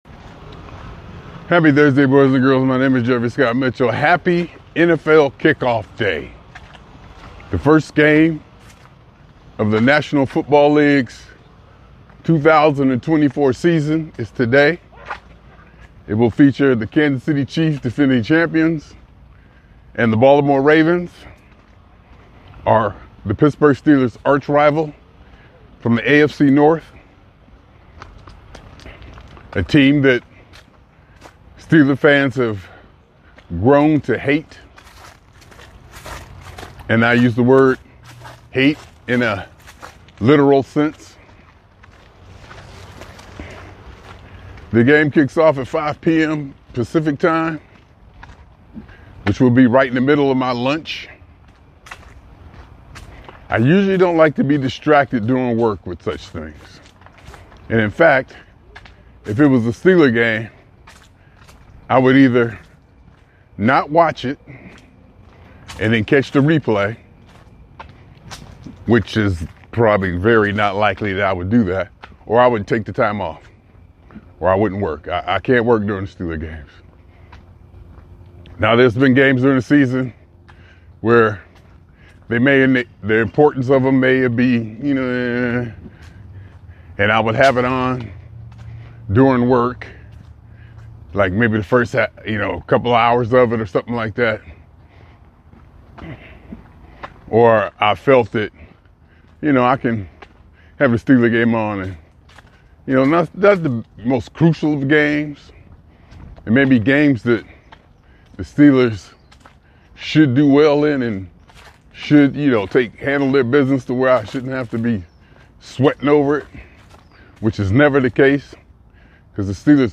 Me Walking Talking November 15, 2020: Randoming About